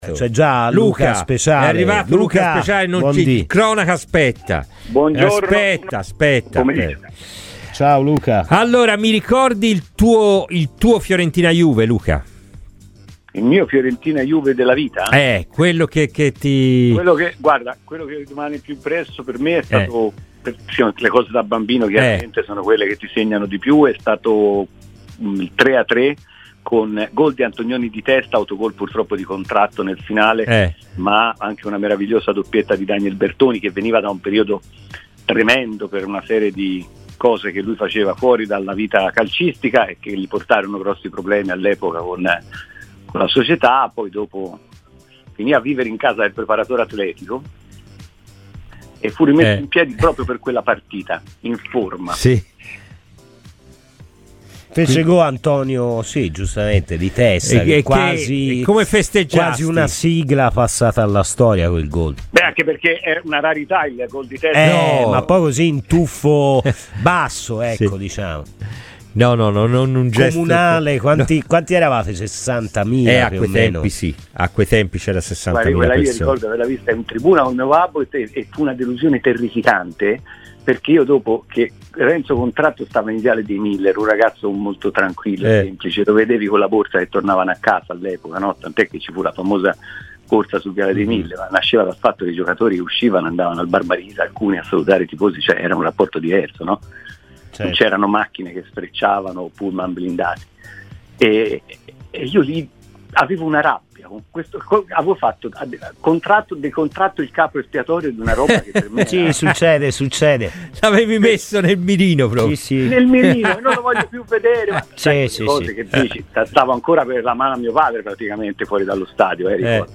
ASCOLTA IL PODCAST PER L'INTERVENTO INTEGRALE.